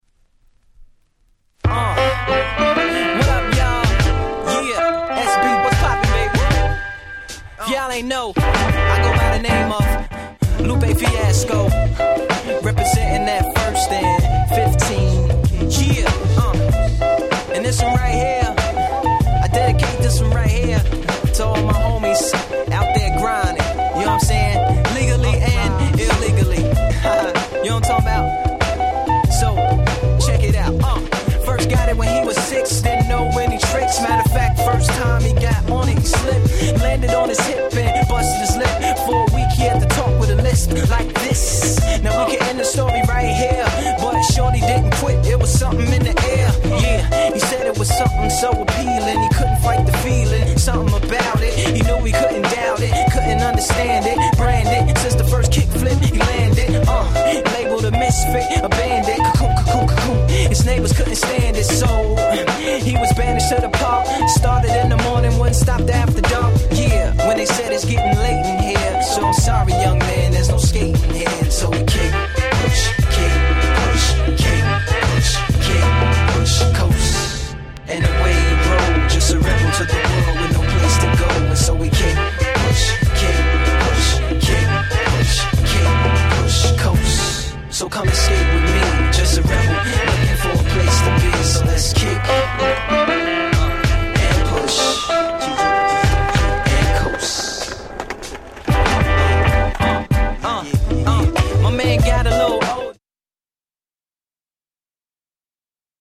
スケボーアンセム！！